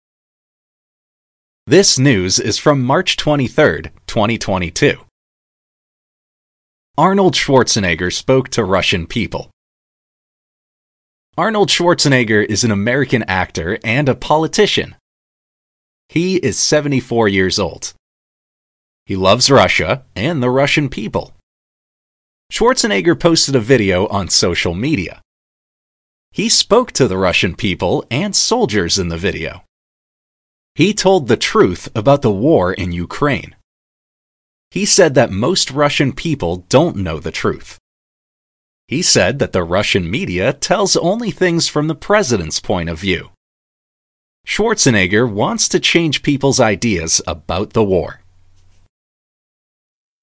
Shadowing